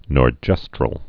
(nôr-jĕstrəl)